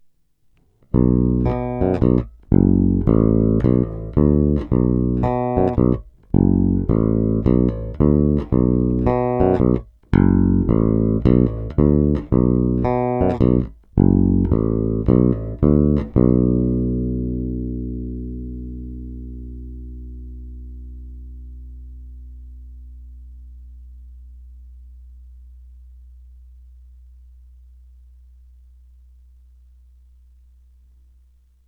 Následující nahrávky jsou provedeny rovnou do zvukové karty a dále ponechány bez jakýchkoli úprav, kromě normalizace samozřejmě. Použité struny jsou neznámé niklové pětačtyřicítky ve výborném stavu.
Snímač u kobylky